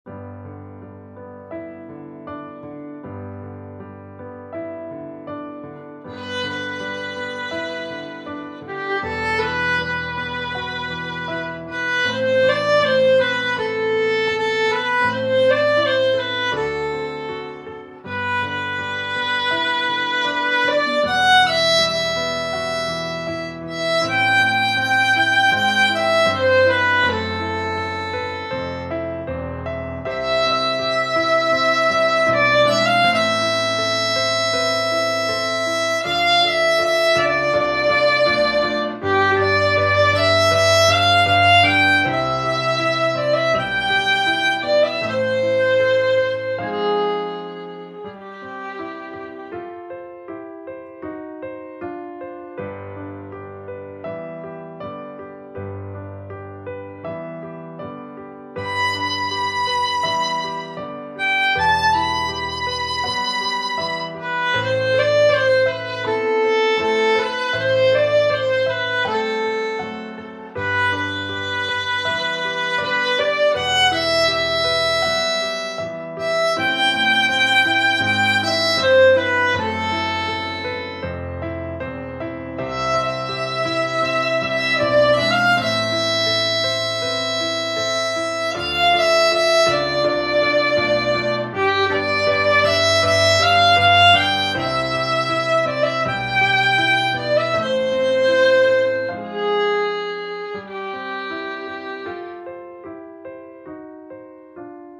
• violin + piano accompaniment